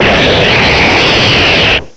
cry_not_dragalge.aif